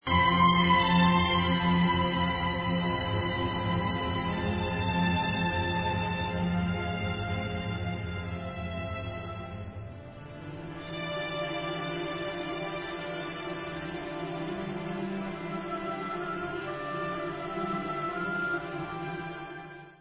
ve studiu Abbey Road